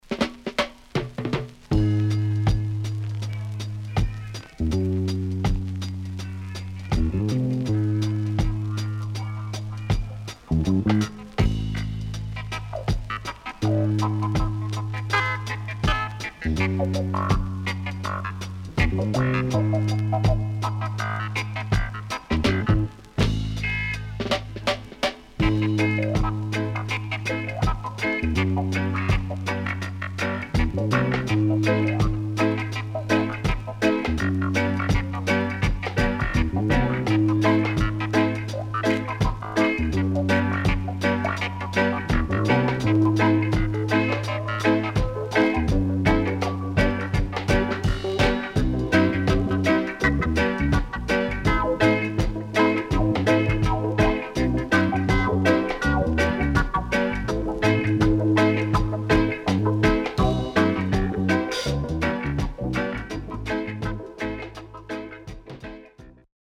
Good Vocal.Good Condition
SIDE A:プレス起因によるノイズありますがそれ以外良好です。